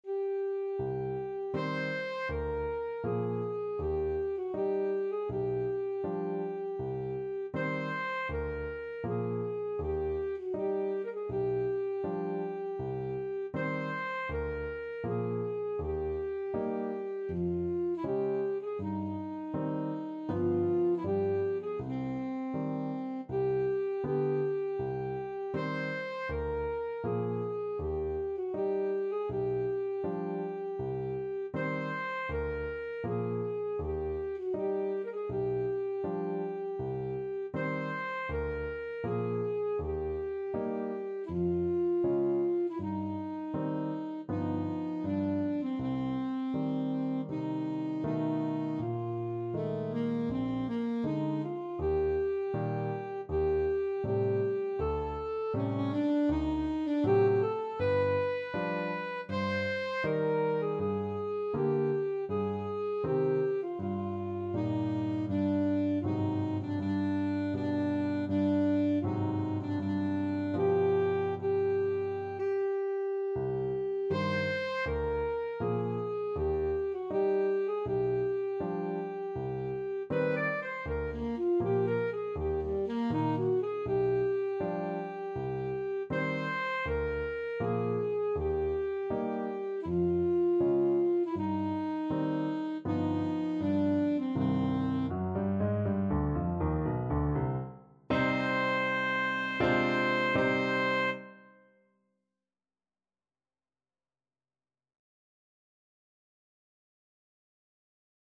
Alto Saxophone
C minor (Sounding Pitch) A minor (Alto Saxophone in Eb) (View more C minor Music for Saxophone )
4/4 (View more 4/4 Music)
Andante = c.80
Classical (View more Classical Saxophone Music)